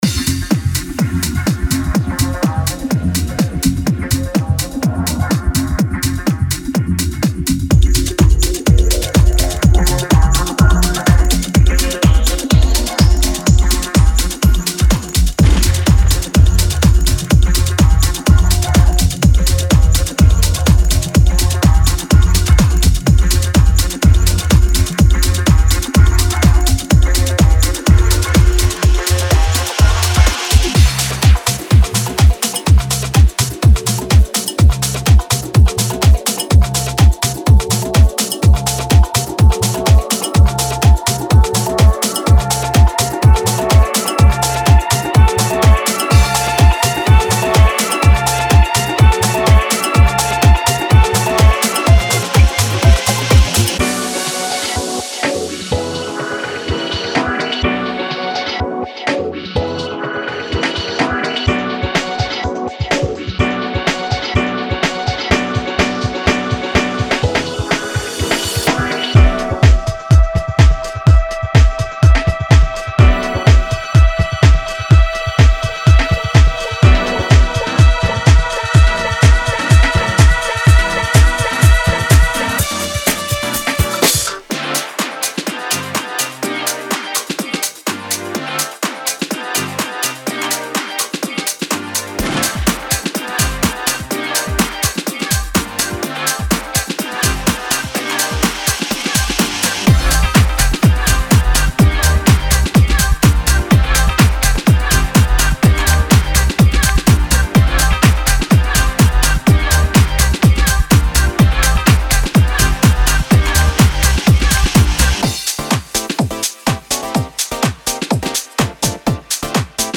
専用のハウス＆ガレージサンプルパックで、控えめなヴィンテージディスコの質感が特徴です。
テンポは120～128 BPMで、多様なジャンルにぴったりです。
・クラシックなガレージグルーヴ：深みのある丸みのあるベースライン、パンチの効いたキック、タイトなハイハット。
デモサウンドはコチラ↓
Genre:Garage